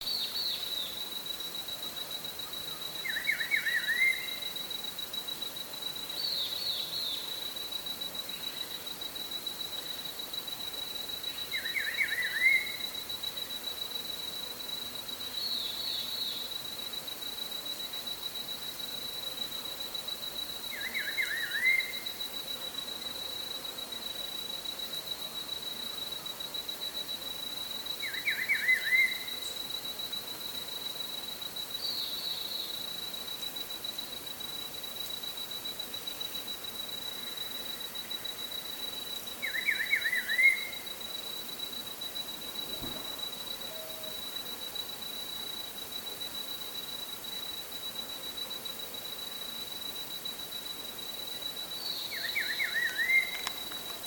Local: RPPN Corredeiras do rio Itajaí, em Itaiópolis - SC
Entre julho e novembro (período reprodutivo), cantam intensamente o melodioso chamado flautado, entendido como os nomes comuns dado à espécie. Uma ave responde à outra, enquanto andam na parte alta e média da mata.